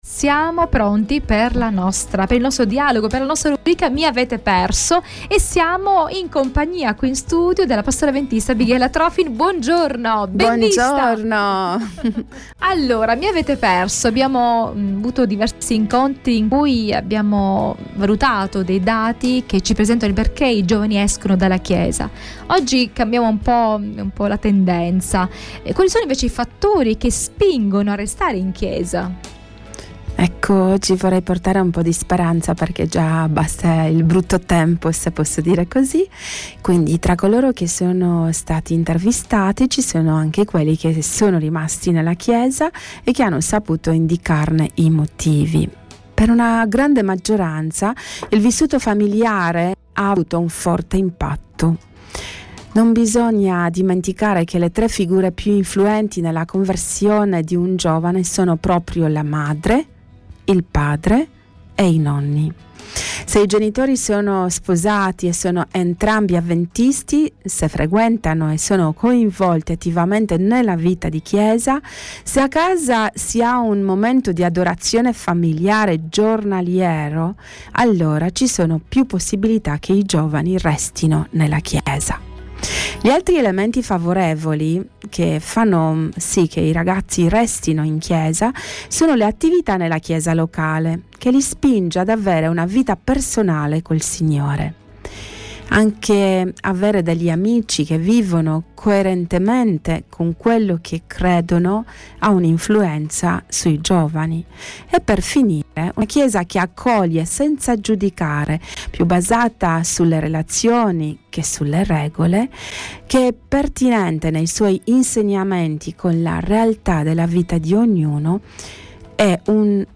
dialogo